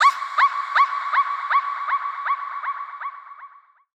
DS_VAH_vocal_shout_female_inhliziyo_wet_C#
DS_VAH_vocal_shout_female_inhliziyo_wet_C.wav